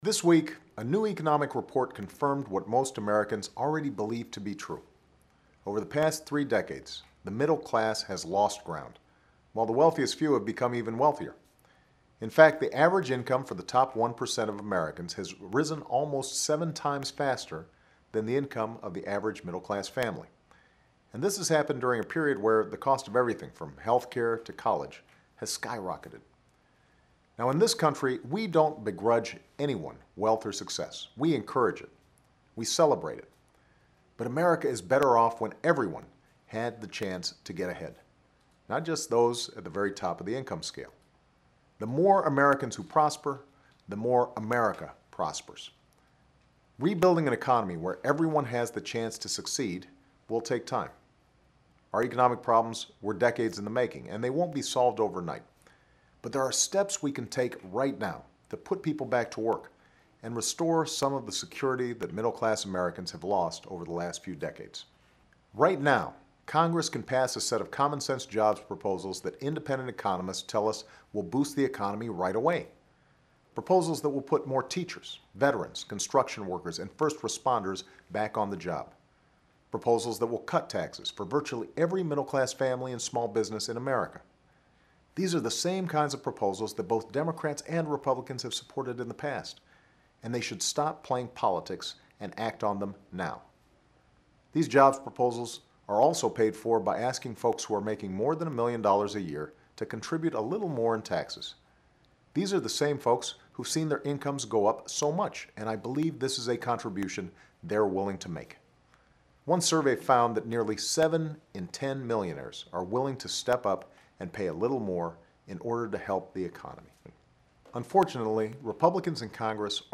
Remarks of President Barack Obama